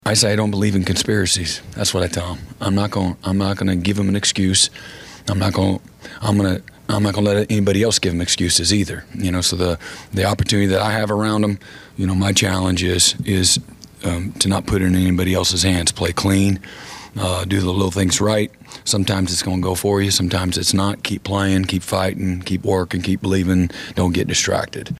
Oklahoma head football coach Brent Venables met with the media yesterday afternoon, ahead of the Sooners penultimate game in the Big 12 – Ever.